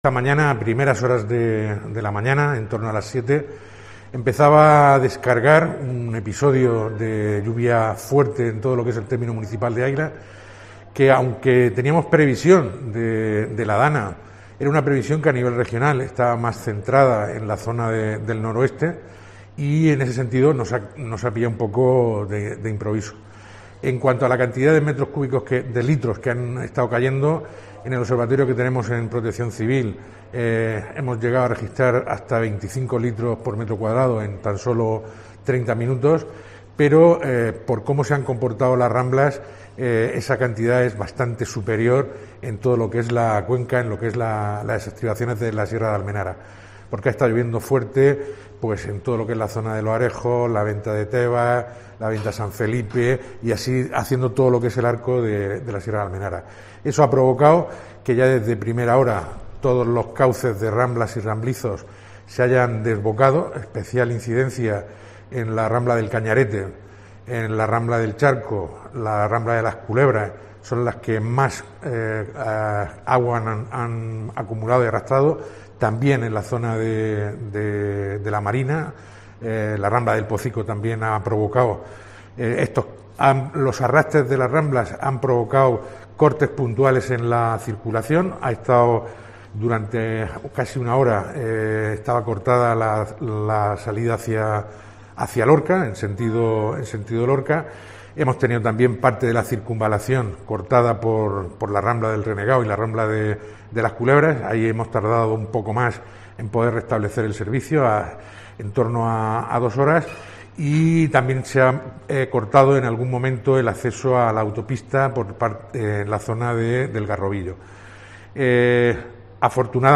Tomás Cosentino Navarro, portavoz Ayuntamiento de Águilas